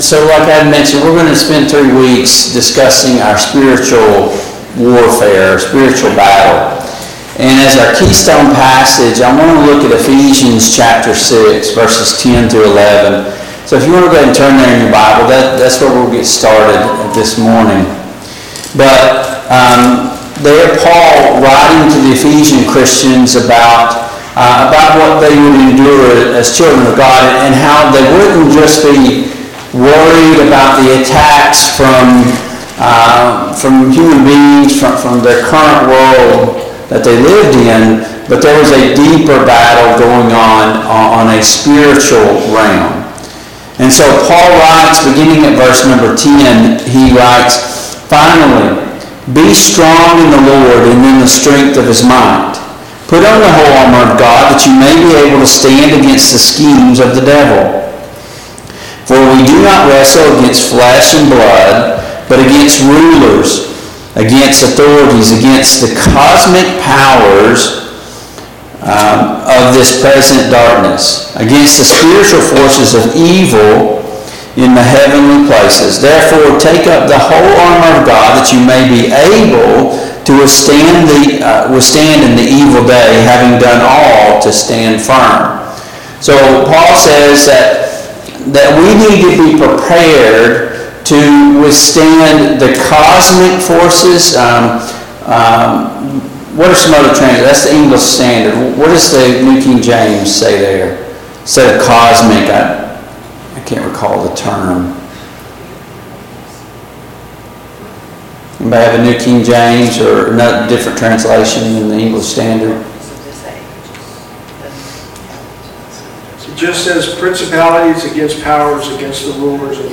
Passage: Ephesians 6:10-13 Service Type: Sunday Morning Bible Class